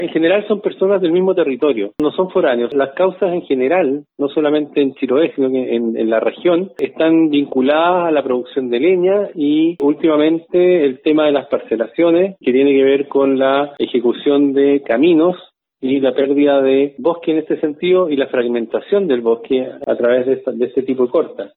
El director regional de la Conaf, Jorge Aichele, revela que los que cortan los bosques son principalmente lugareños de la región y además detalló los motivos que llevan a la realización de estas acciones.